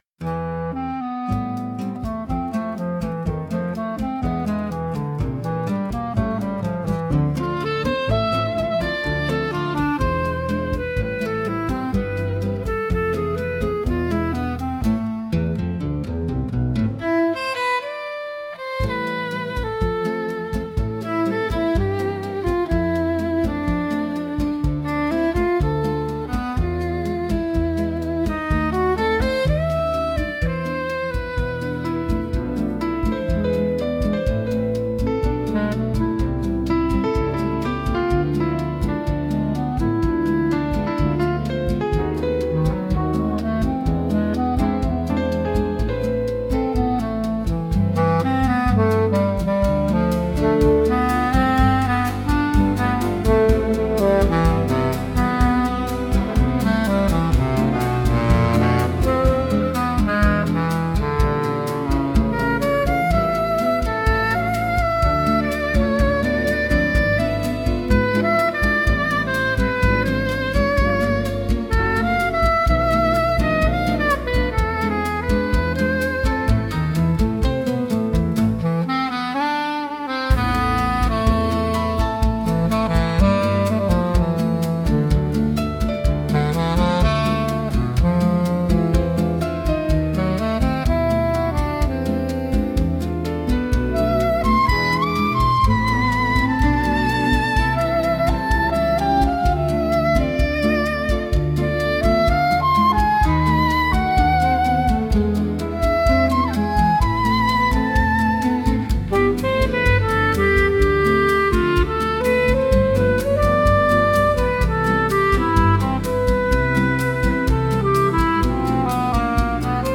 música e arranjo: IA) (Instrumental)